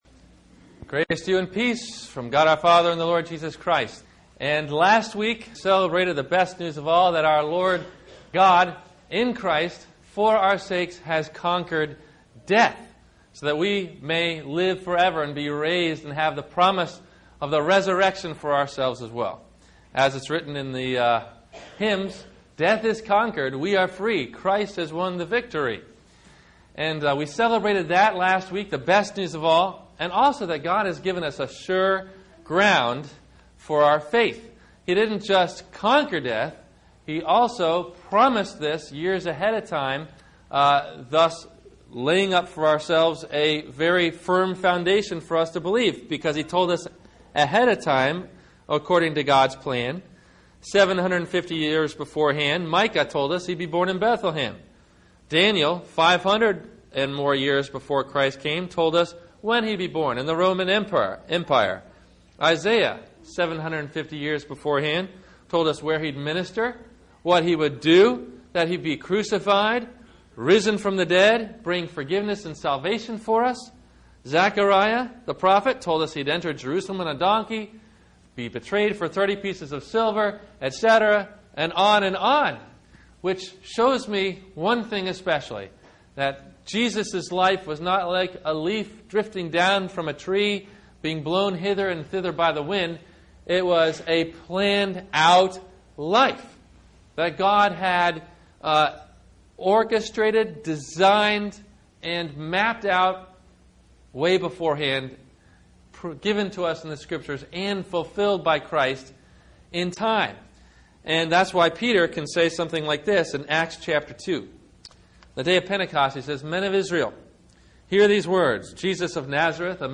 Does God Have A Plan For Your Life - Sermon - April 19 2009 - Christ Lutheran Cape Canaveral